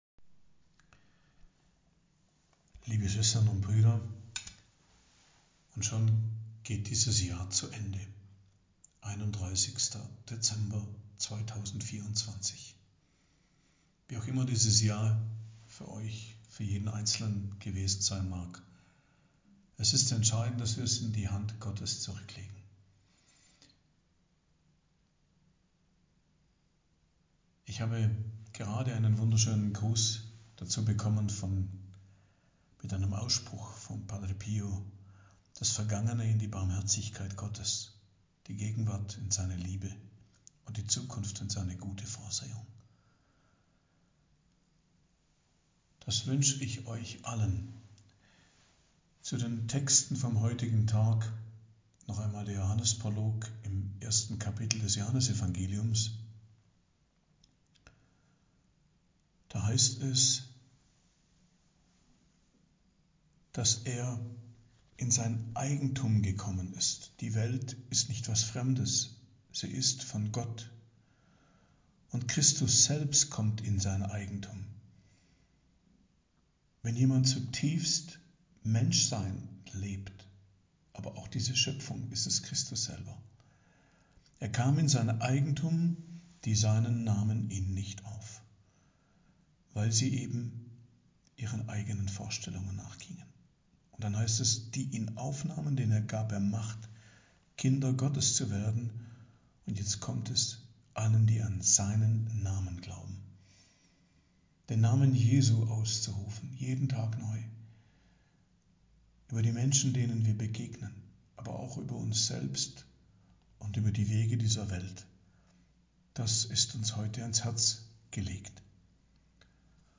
Predigt am 7. Tag der Weihnachtsoktav , 31.12.2024